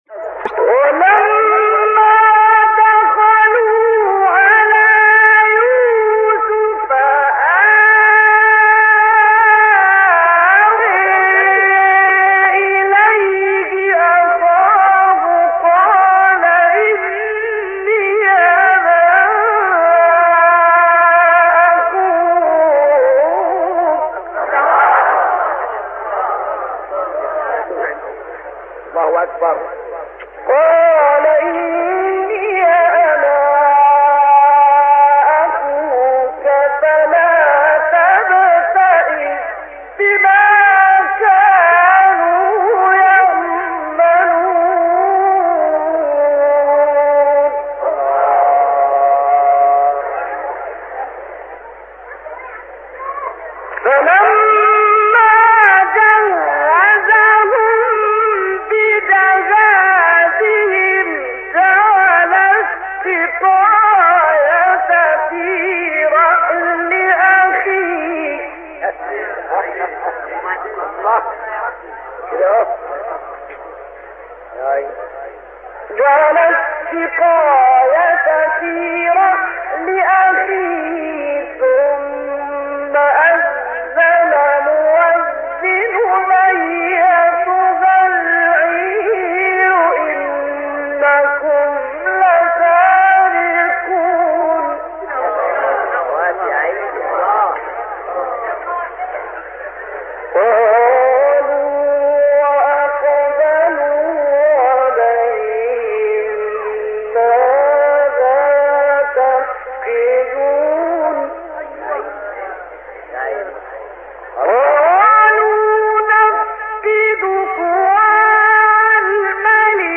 آیه 69-72 سوره یوسف استاد محمد عبدالعزیز حصان | نغمات قرآن | دانلود تلاوت قرآن